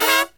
FALL HIT07-R.wav